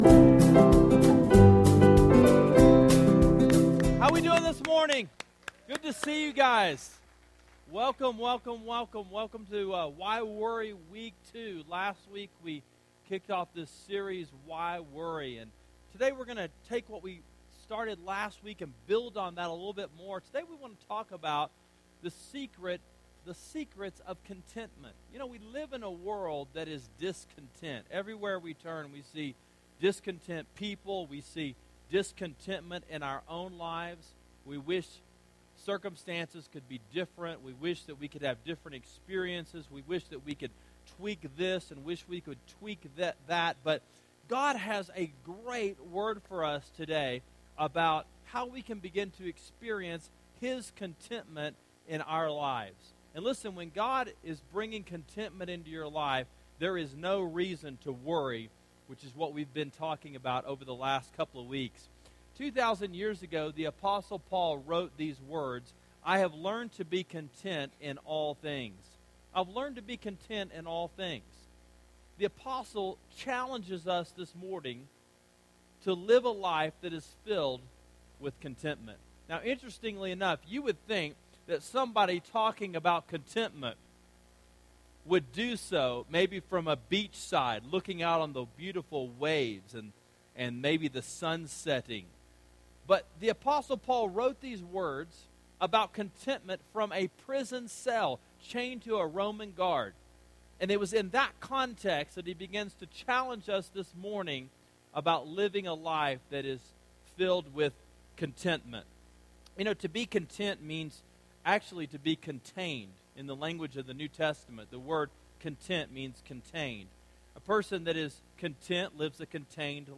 Why Worry: The Secrets of Contentment: Philippians 4:10-13 – Sermon Sidekick